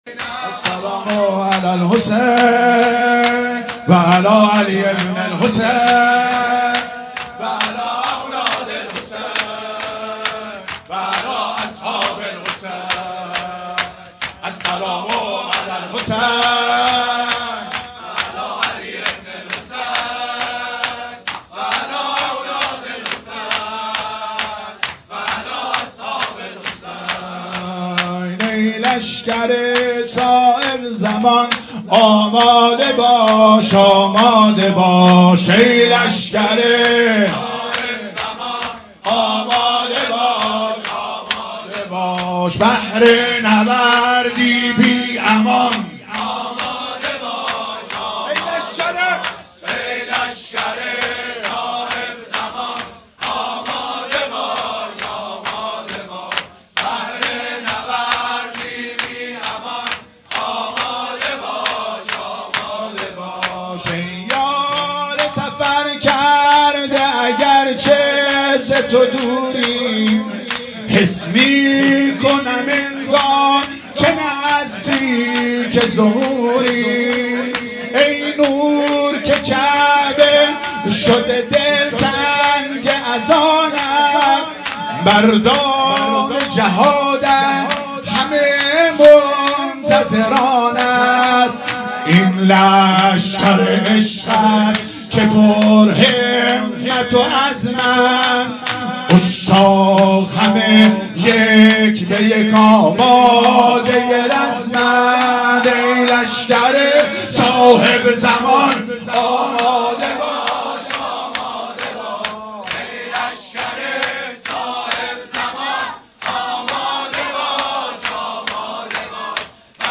شور | ای لشکر صاحب زمان آماده باش آماده باش مداح